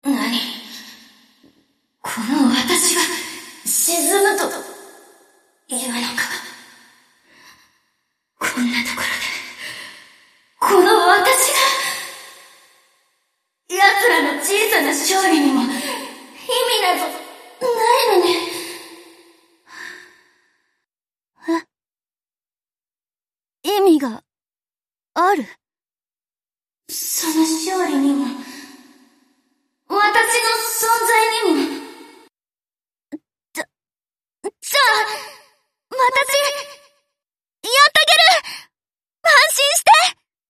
Enemy_Voice_New_Heavy_Cruiser_Princess_Damaged_Sunk.mp3